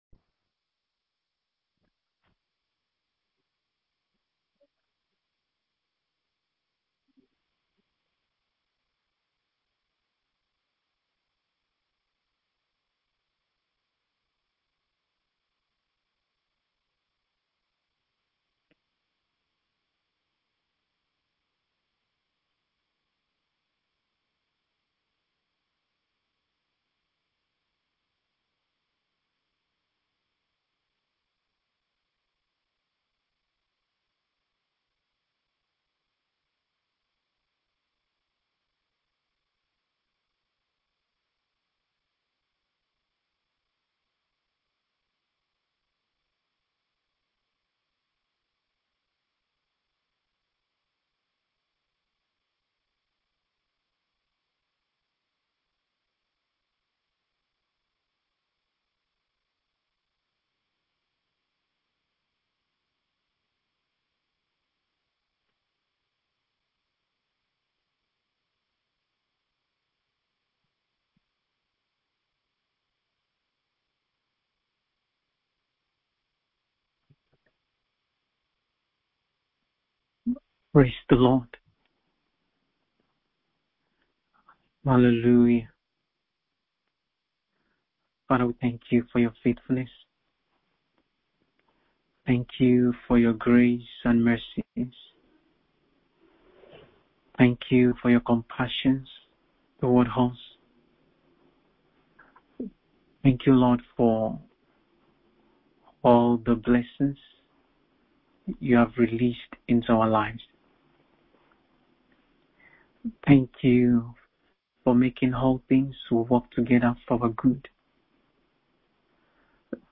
BIBLE STUDY _ END TIME PERSECUTION, 1) SUFFERING PERSECUTION IN THE LAST DAYS _2) PERSECUTION DURING THE GREAT TRIBULATION _3) THE TRIBULATION SAINTS WILL BE GREATLY REWARDED